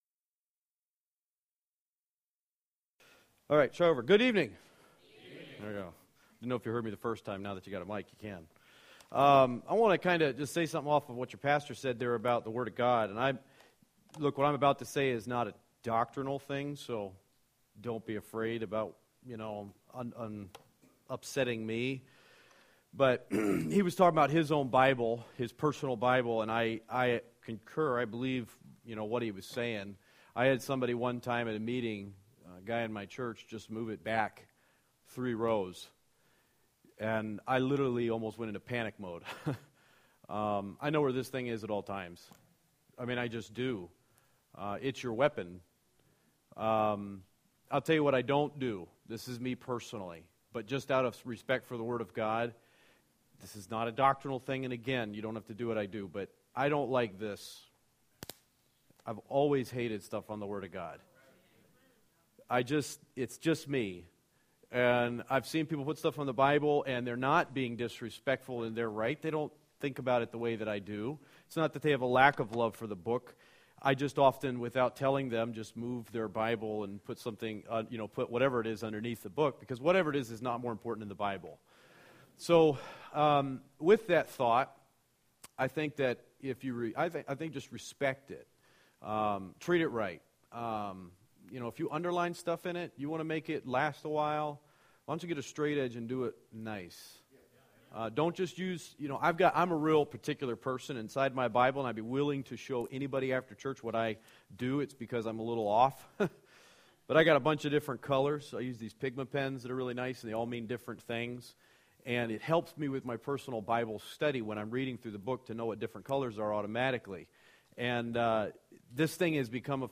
A message preached at the Heritage Baptist Church, Post Falls, Idaho on February 23